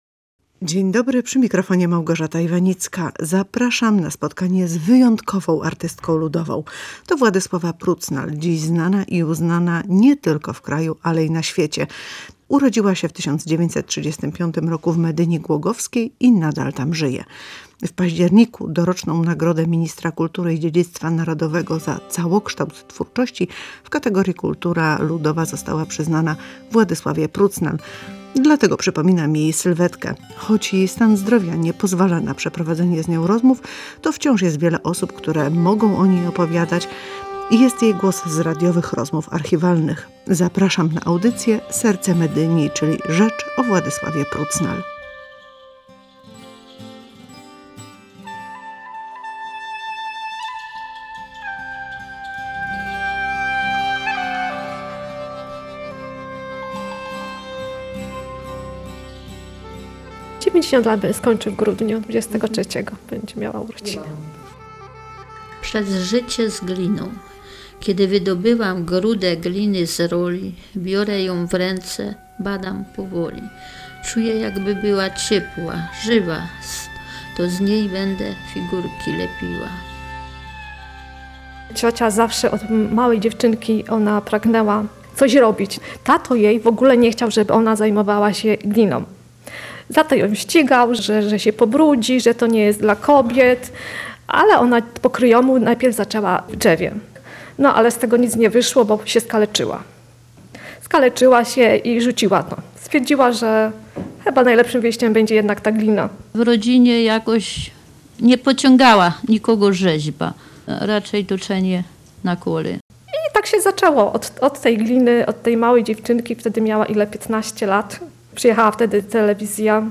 Usłyszą państwo także głos artystki ludowej z archiwalnych rozmów radiowych.